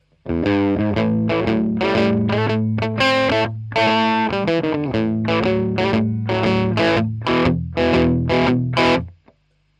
Der Klang ist harscher als bei der Röhre, hohe Frequenzanteile sind deutlich stärker präsent.
Klang eines Transistorverstärkers
Bluesy
harley_benton_04_bluesy_.mp3